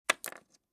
StoneSound6.mp3